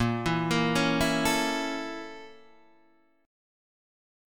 A#mM7b5 chord {6 7 8 6 5 5} chord